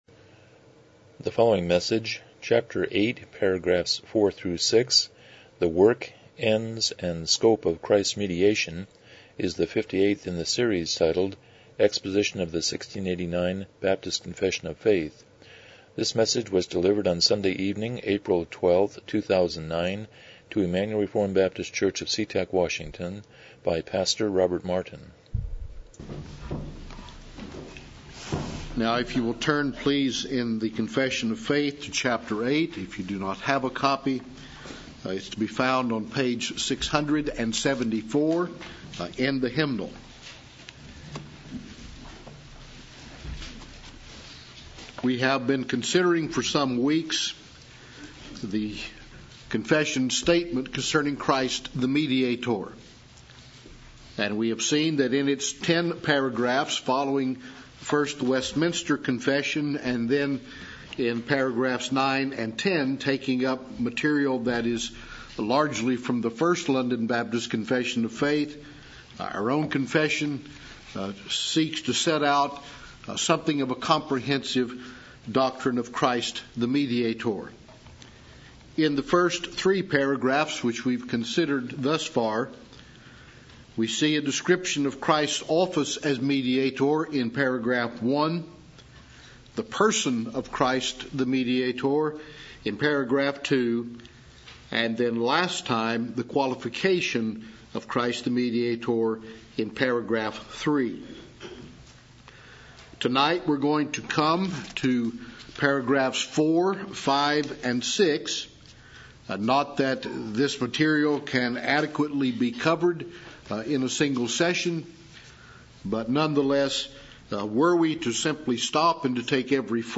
Series: 1689 Confession of Faith Service Type: Evening Worship